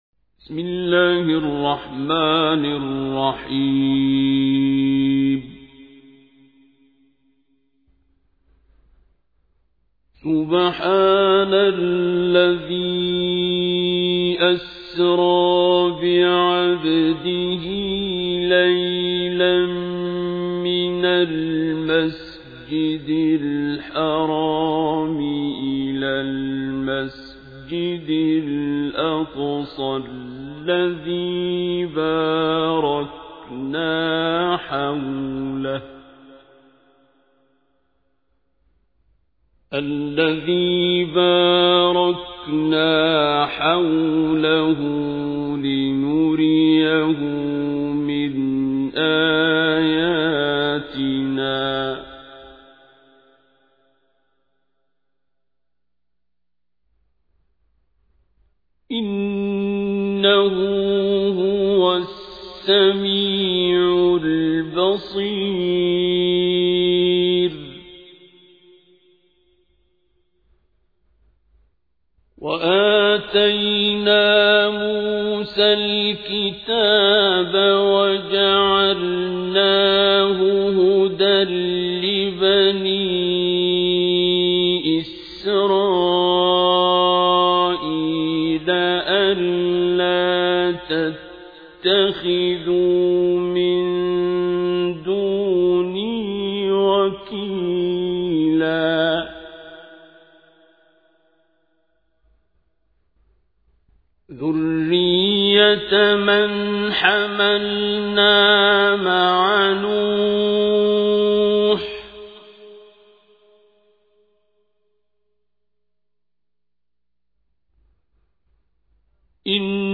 تحميل : 17. سورة الإسراء / القارئ عبد الباسط عبد الصمد / القرآن الكريم / موقع يا حسين